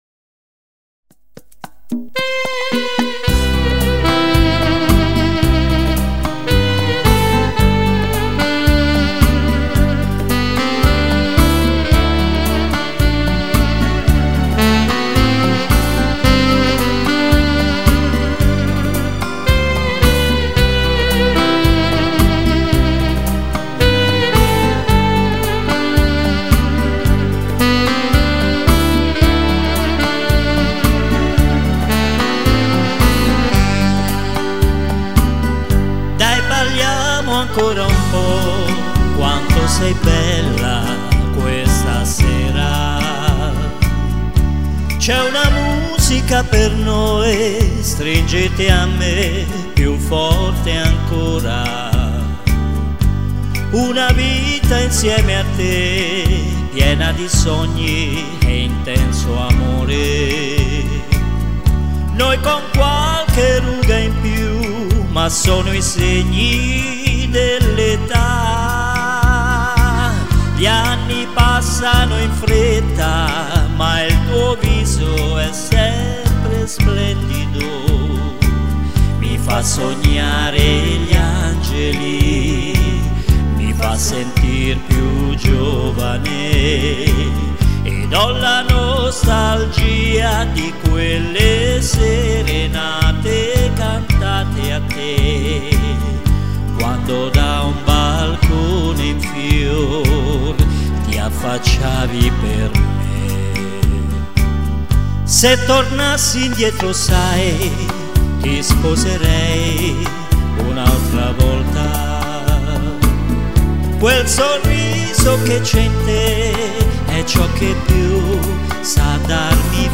Canzoni e musiche da ballo
rumba